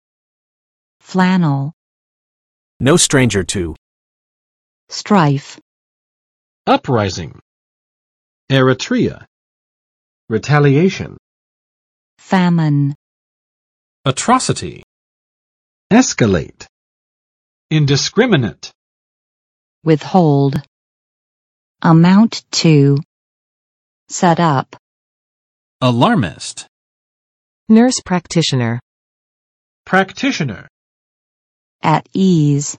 [ˋflæn!] n. 法兰绒；棉绒